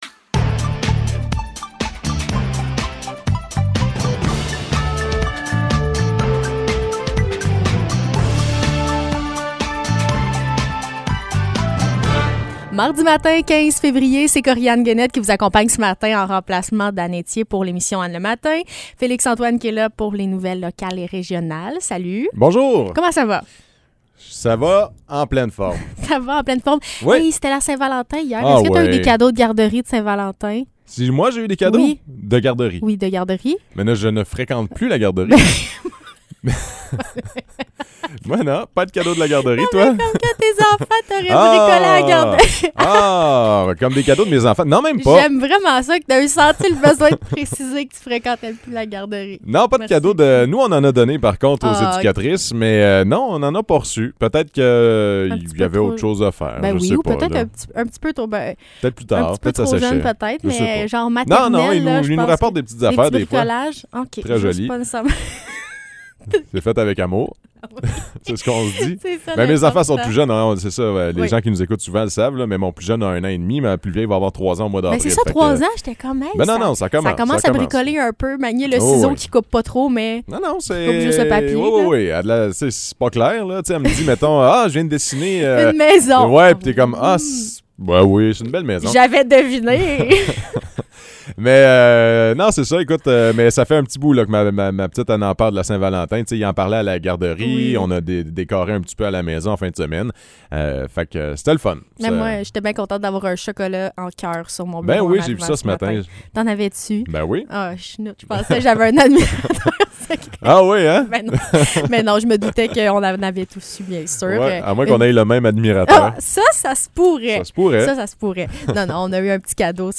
Nouvelles locales - 15 février 2022 - 9 h